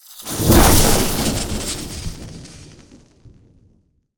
elec_lightning_magic_spell_11.wav